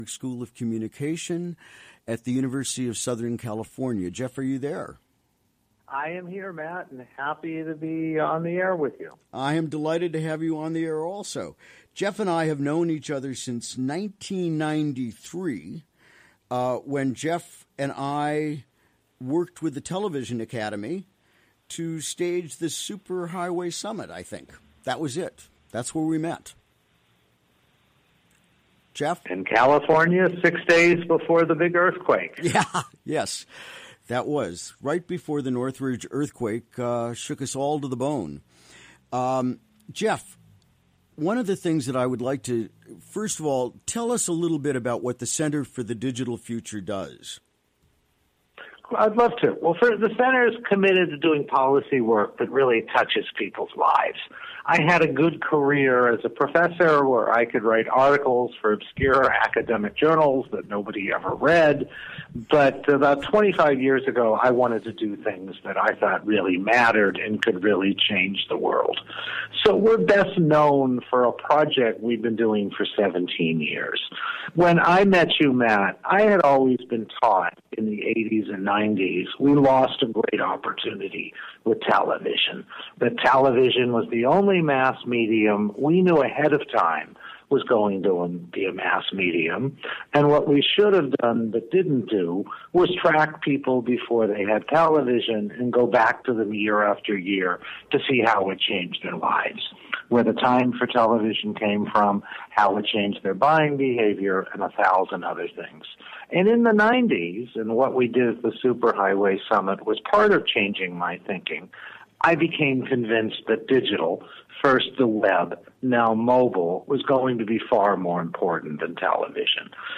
Recorded during the WGXC Morning Show on Wednesday, May 3.